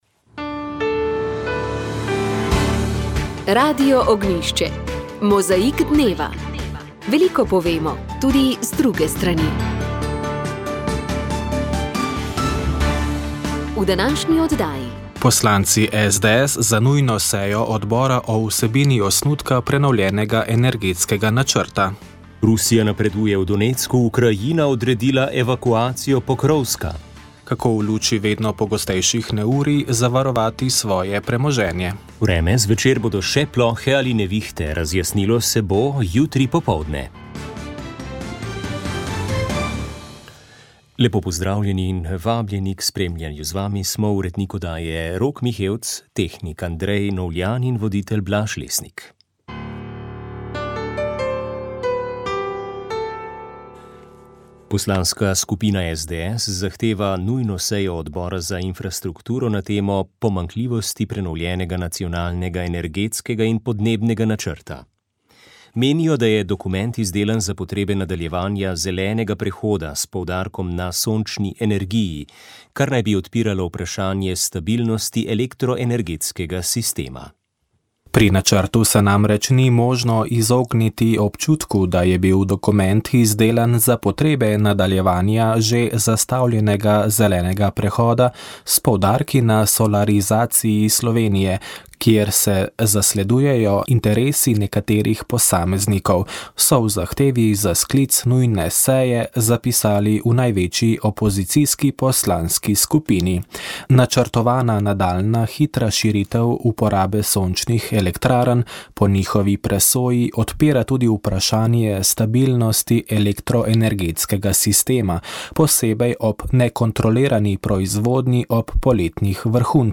3. pogovor ob filmu Dva brata, dve sestri